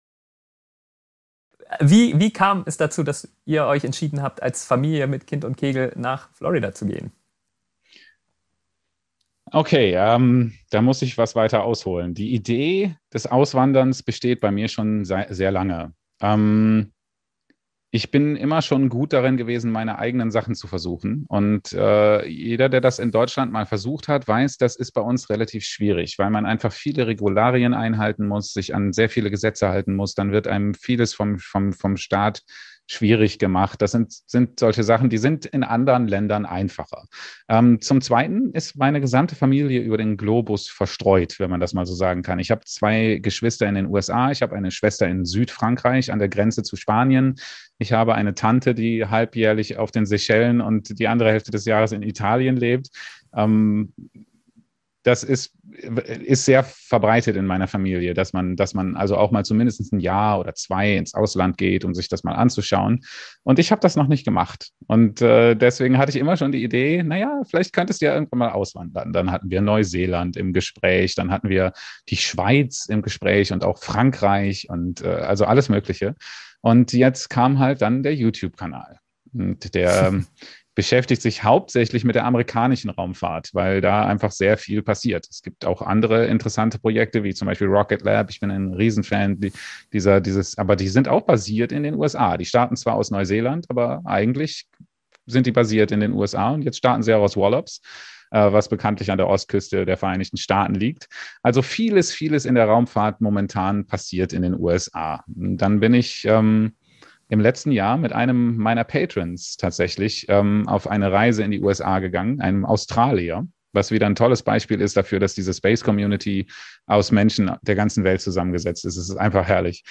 Ich hab den Livestream geschnitten und mit Untertiteln versehen.